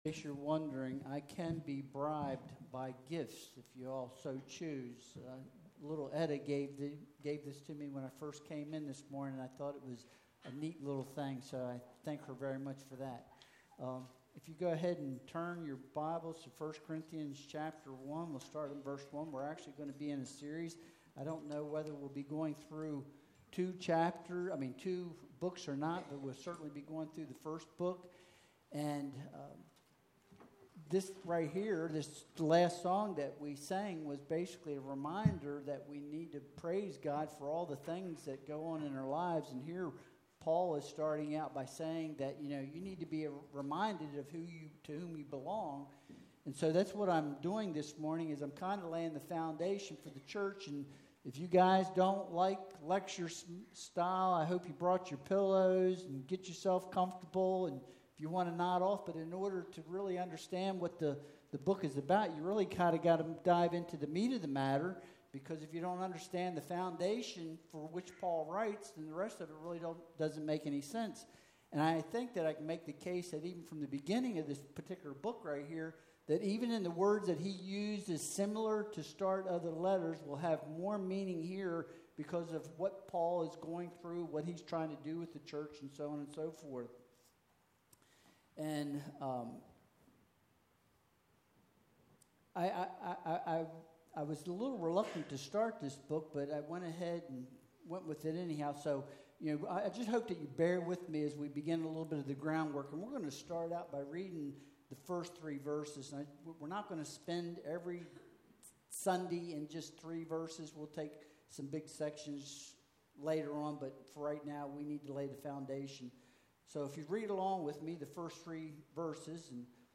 Passage: 1 Corinthians 1.1-3 Service Type: Sunday Worship Service Download Files Bulletin « How Do You Respond to Evil?